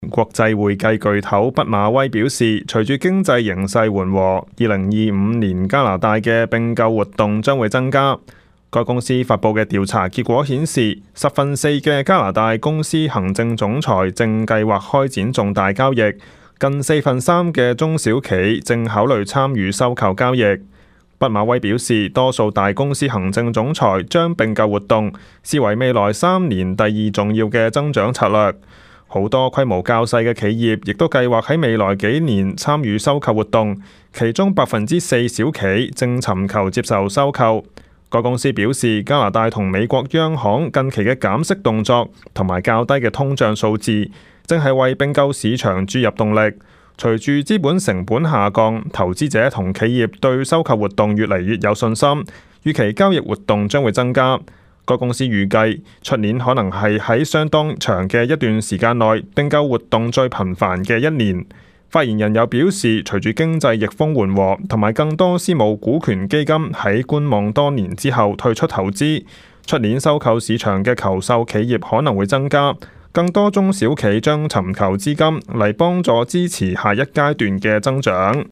news_clip_20826.mp3